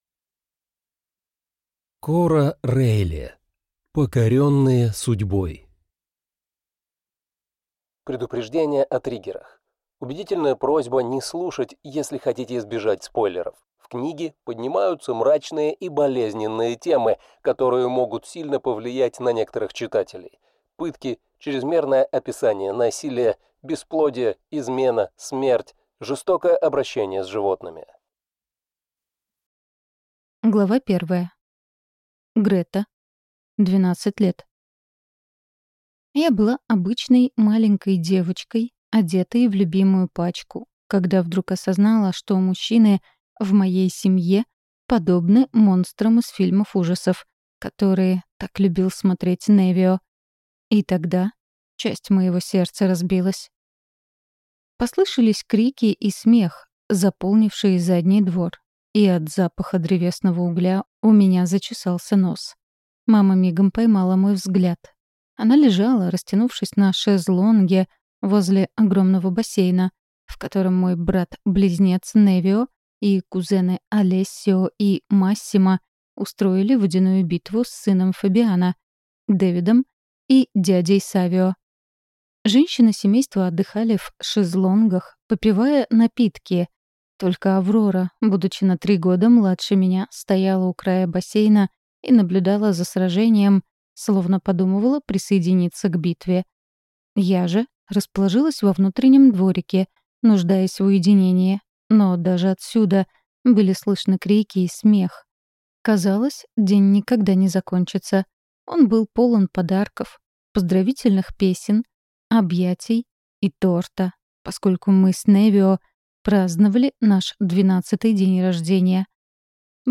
Драгун. На задворках империи (слушать аудиокнигу бесплатно) - автор Андрей Булычев